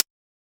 UHH_ElectroHatC_Hit-09.wav